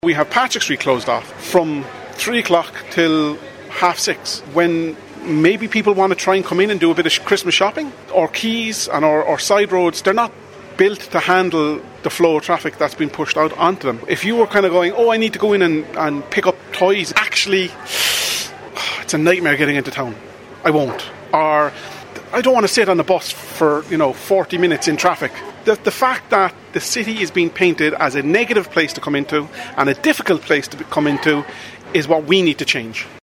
Speaking to RedFM News